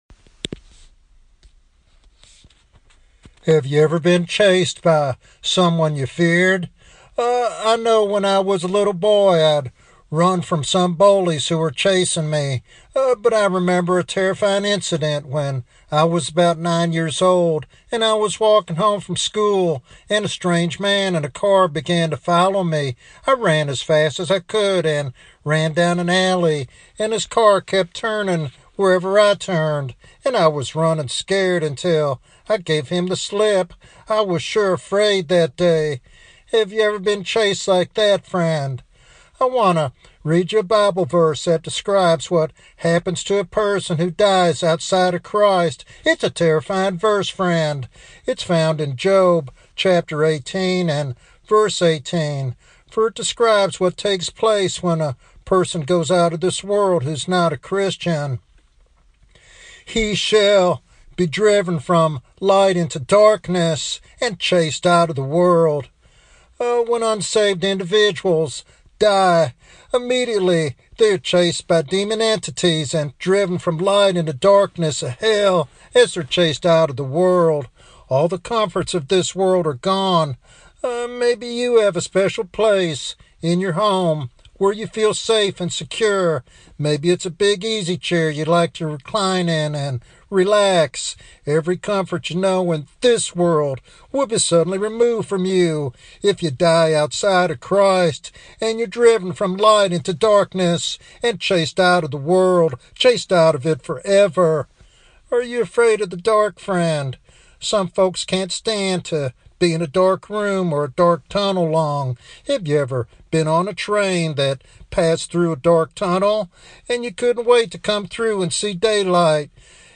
He challenges believers and church members alike to examine their salvation and calls for genuine repentance and spiritual transformation. This sermon is a powerful reminder of the urgency of knowing Christ personally.